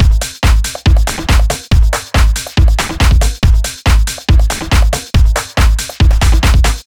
Сэмплы ударных (Техно-транс): Elementary Beat A
Тут вы можете прослушать онлайн и скачать бесплатно аудио запись из категории «Techno Trance».
Sound_09899_ElementaryBeatA.ogg